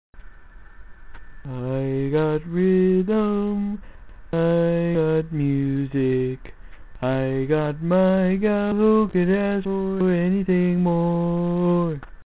Key written in: D♭ Major
Each recording below is single part only.